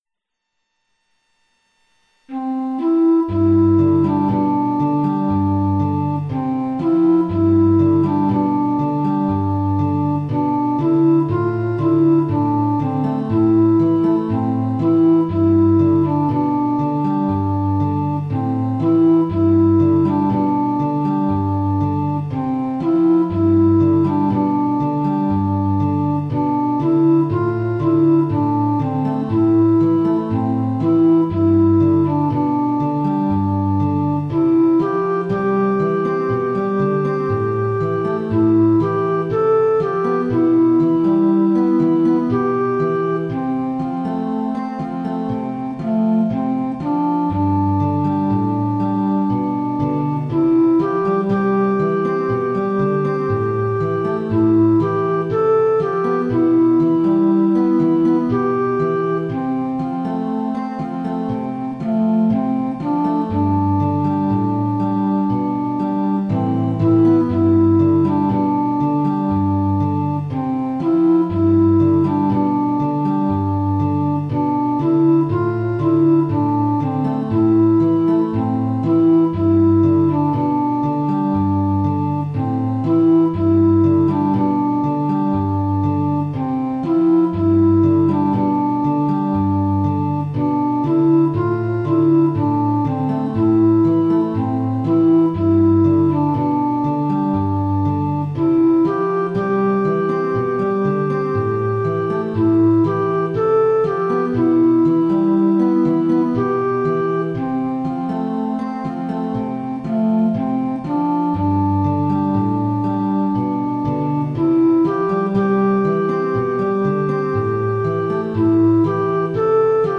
Psalm 125. Those Who Trust in You. A quiet affirmation of faith.